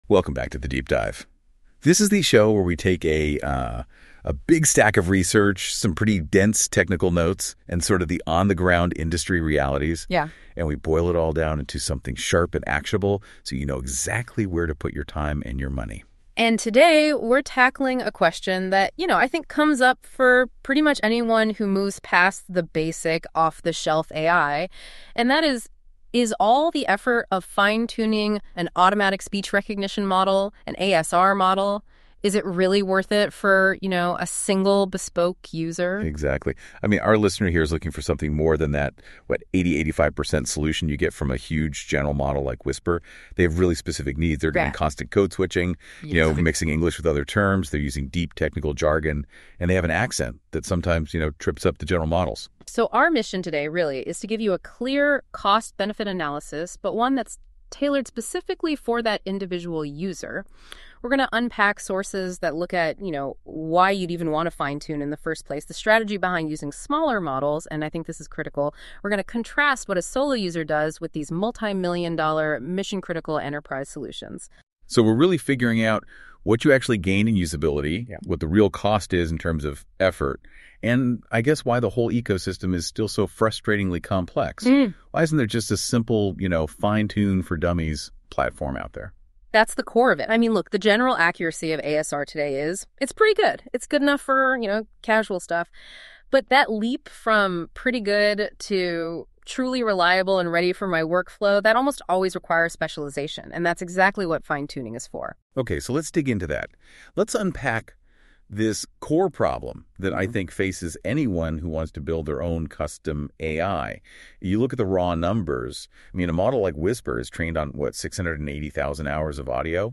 AI-Generated Content: This podcast is created using AI personas. Please verify any important information independently.